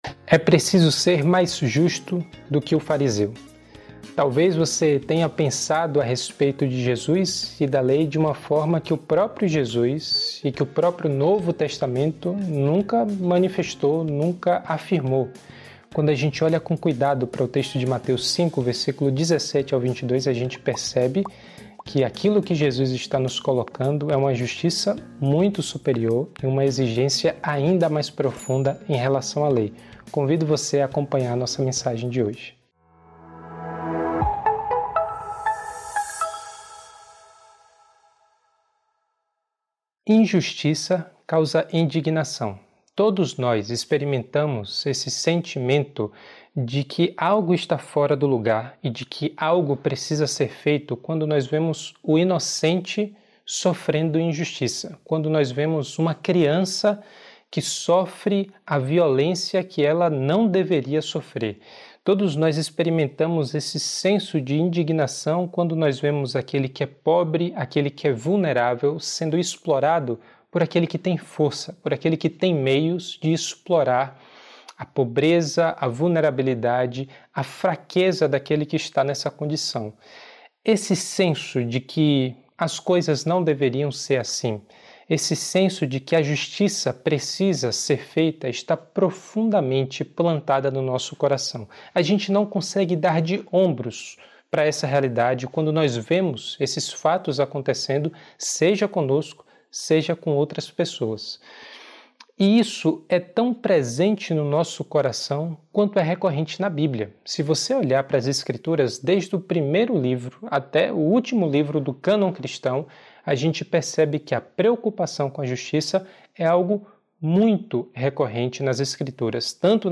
Igreja Batista Nações Unidas